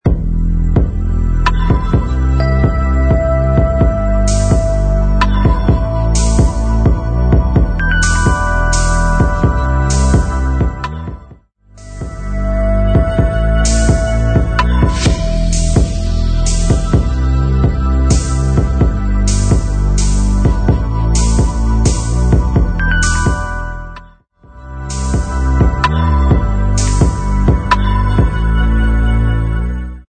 Dark Calm Electronic